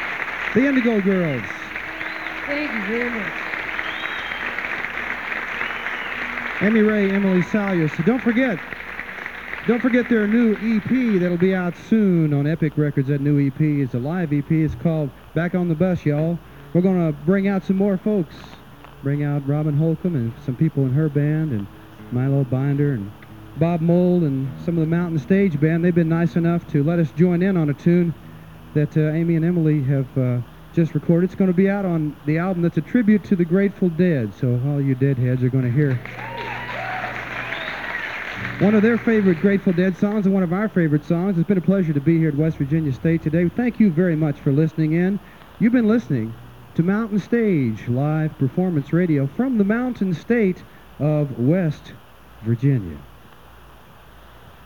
lifeblood: bootlegs: 1991-04-07: capitol theater - charleston, west virginia (mountain stage) (alternate)
11. announcer (0:54)
(radio broadcast)